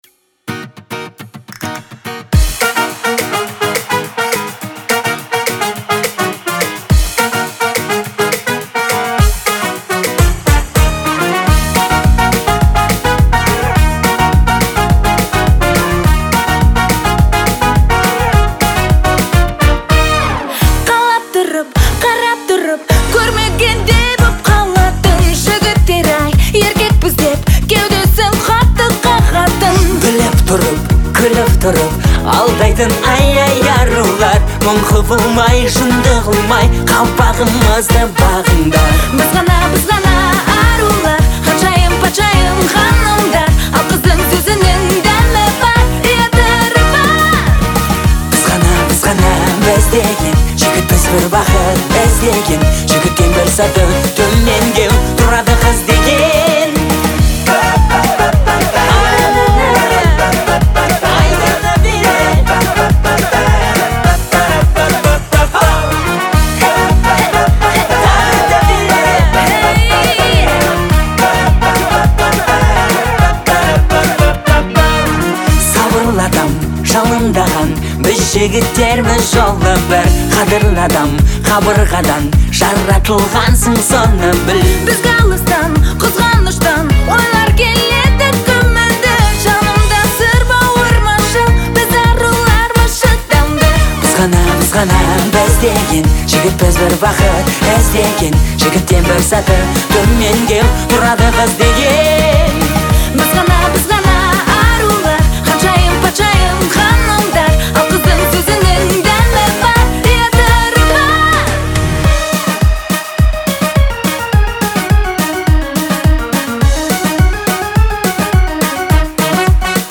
это яркий и энергичный трек в жанре поп